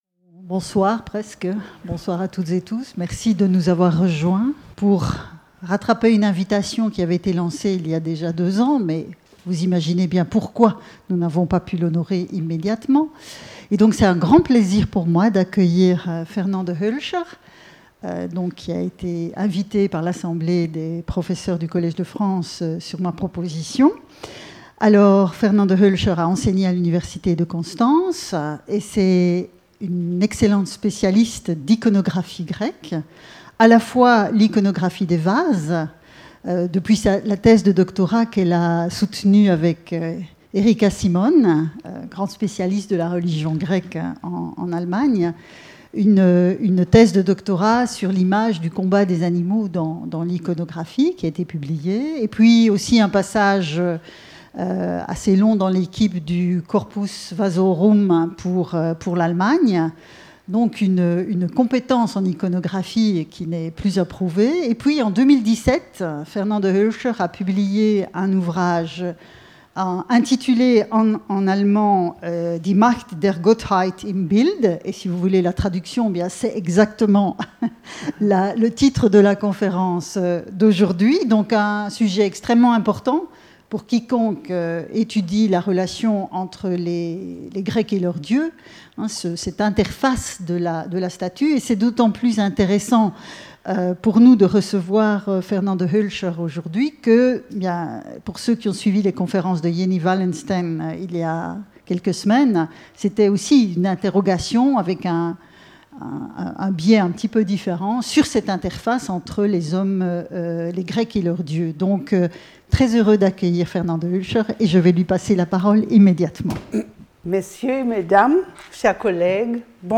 est invitée par l'Assemblée du Collège de France